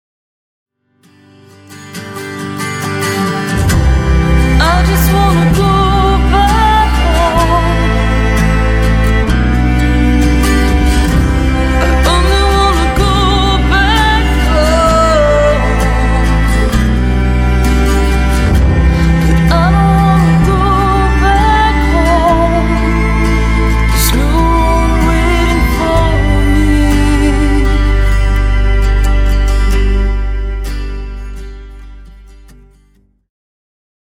all string parts are performed on cello